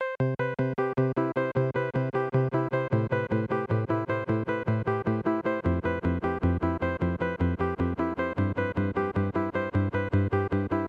寒冷的琶音合成器1
描述：一个7/8拍的琶音循环。用KORG Microkorg制作，用ZOOM H2N录制。
标签： 154 bpm Chill Out Loops Synth Loops 1.83 MB wav Key : Unknown
声道立体声